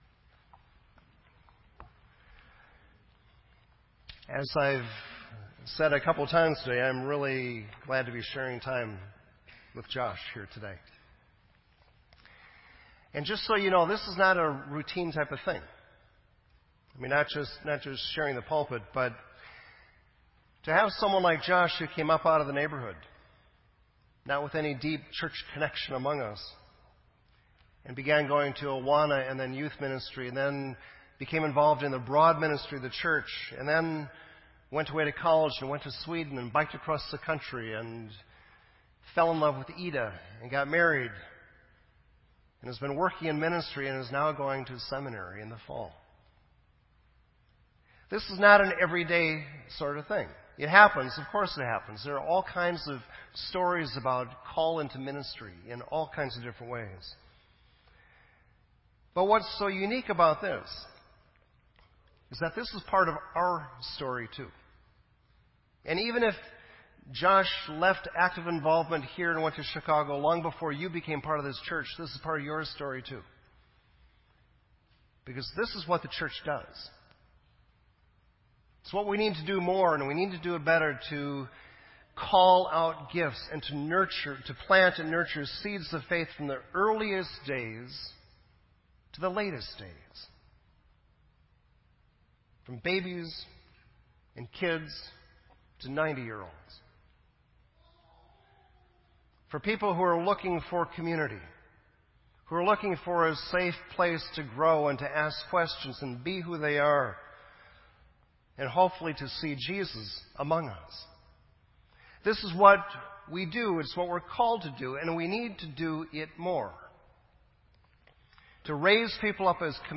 This entry was posted in Sermon Audio on June 20